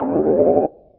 sci-fi_alarm_warning_loop_04.wav